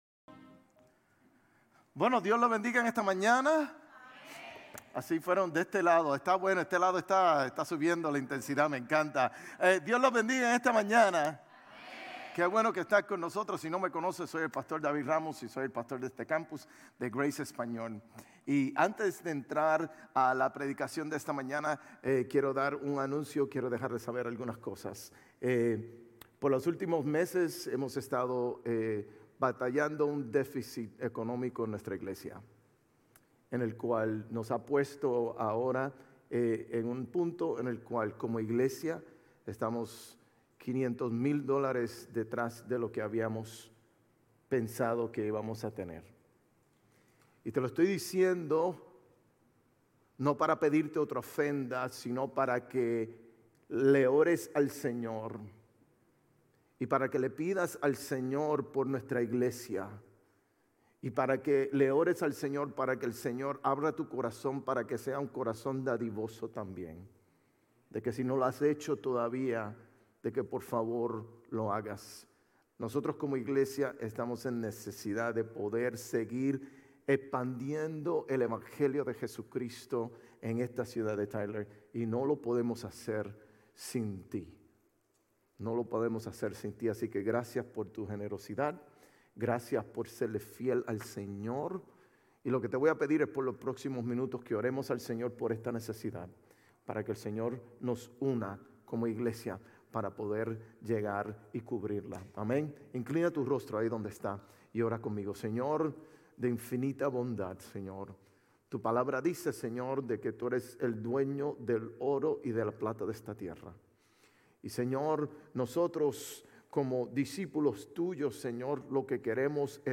Sermones Grace Español 4_6 Grace Espanol Campus Apr 07 2025 | 00:46:26 1x 00:00 / 00:46:26 Subscribe Share RSS Feed Share Link Embed